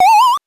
powerup_29.wav